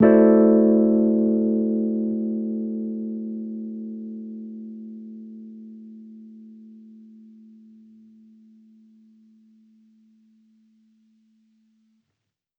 Index of /musicradar/jazz-keys-samples/Chord Hits/Electric Piano 1
JK_ElPiano1_Chord-Am13.wav